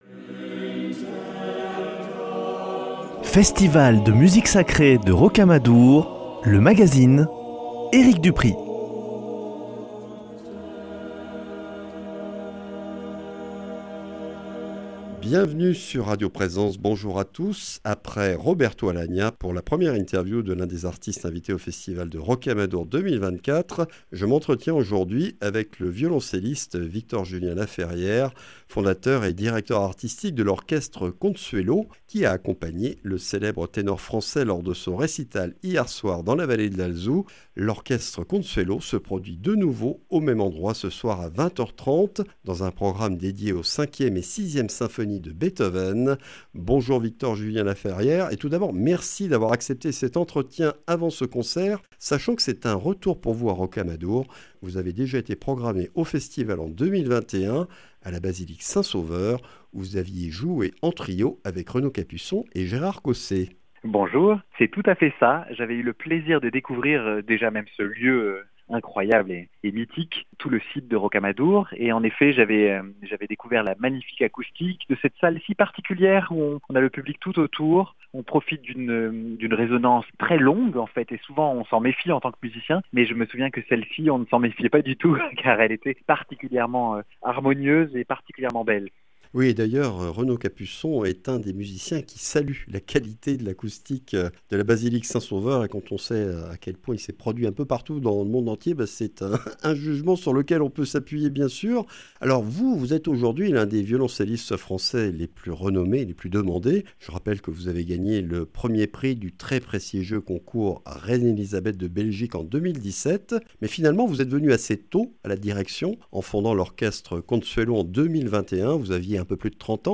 Festival de Rocamadour 2024 : ITW de Victor Julien-Laferrière